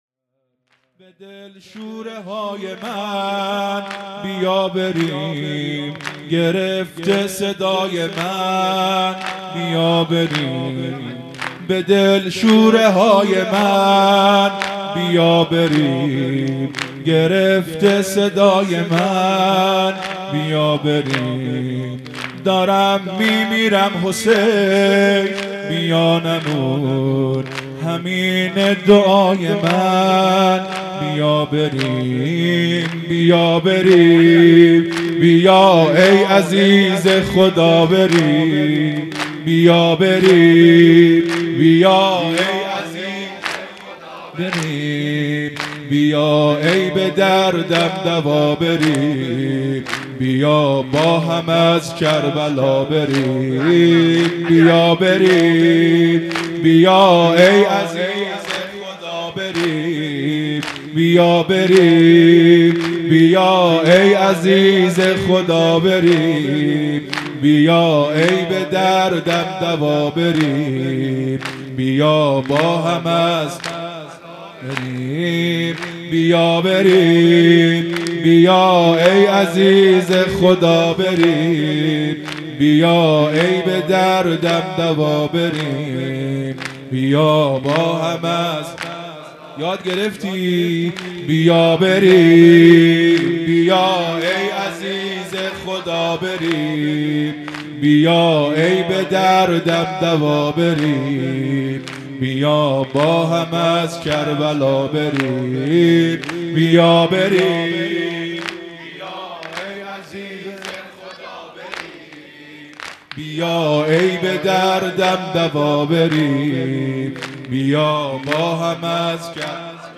زمینه | به دل شوره های من بیا بریم مداح
مراسم عزاداری محرم الحرام ۱۴۴۳_شب دوم